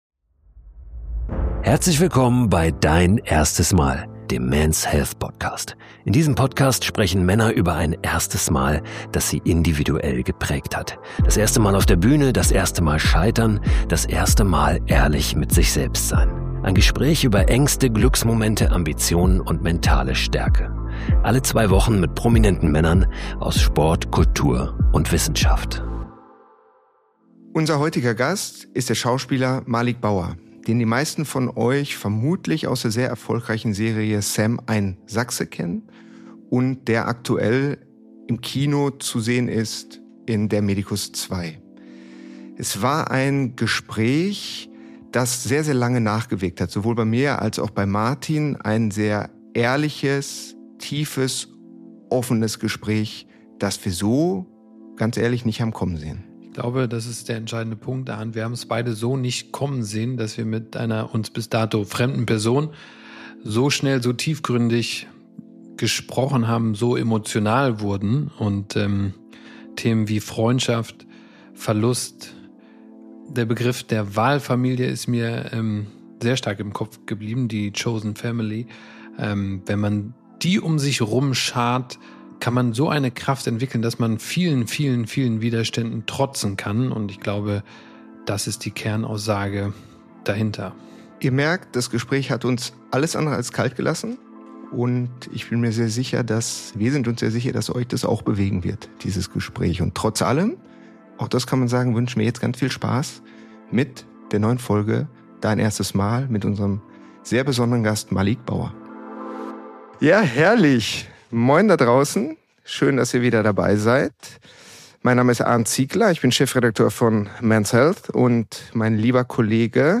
Nicht zuletzt geht es aber natürlich auch um Malick Bauers schauspielerischen Durchbruch mit "Sam, ein Sachse" und den zweiten Teil des Filmhits "Der Medicus", der am 25.12. in den deutschen Kinos anläuft. Ein Gespräch, so vielseitig wie Bauer selbst.